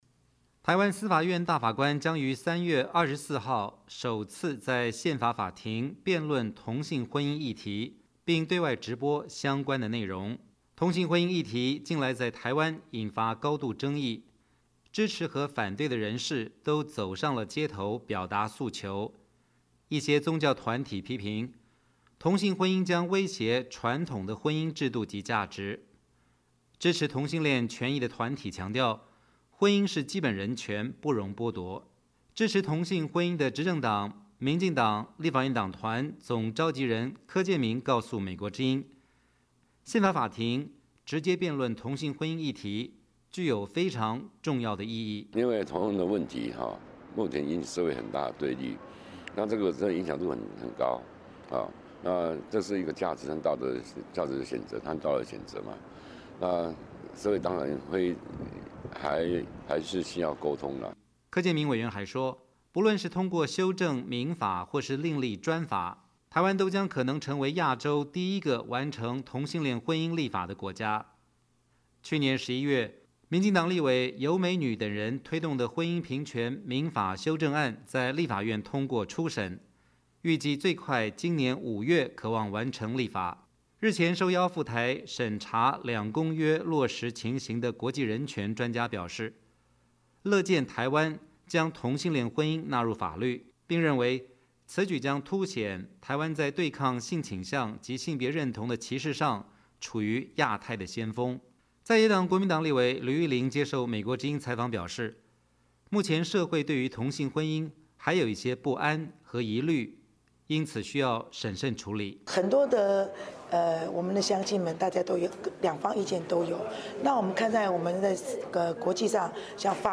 在野党国民党立委吕玉玲接受美国之音采访表示，目前社会对于同性婚姻还是有一些不安和疑虑，因此需要审慎处理。